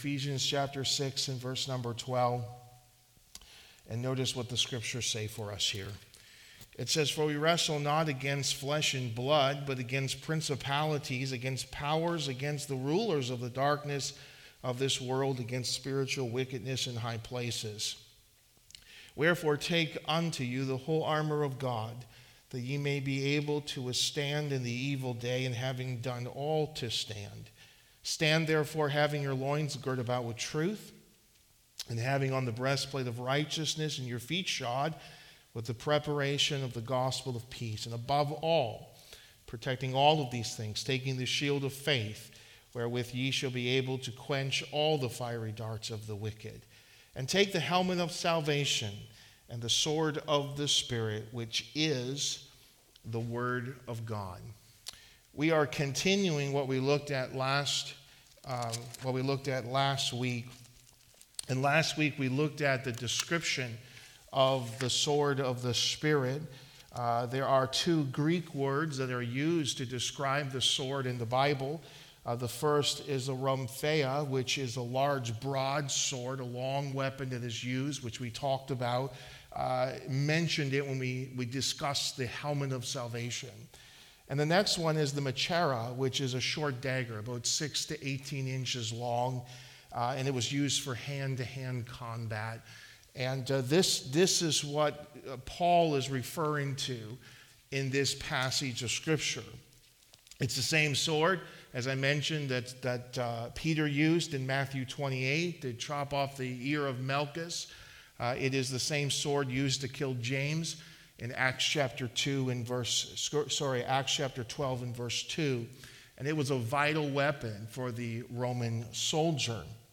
| Sermons